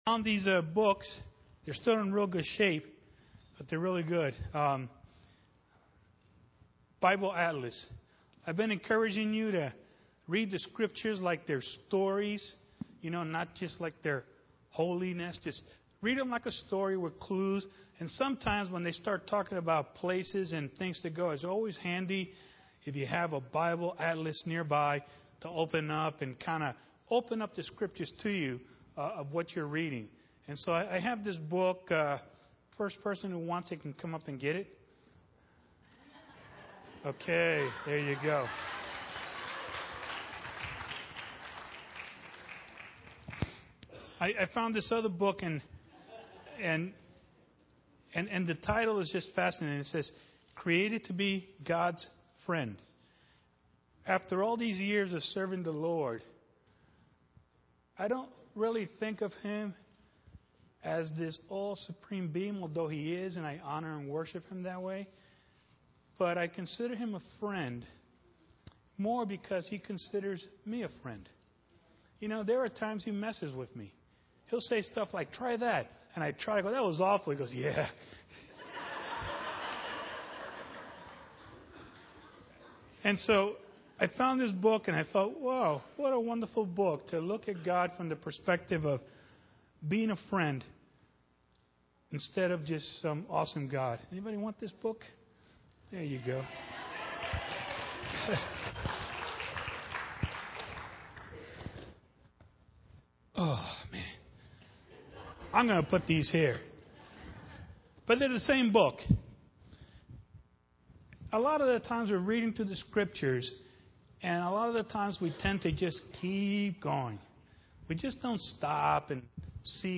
A sermon
presented at Our Father's House Assembly of God in California, MD on 3/17/2013.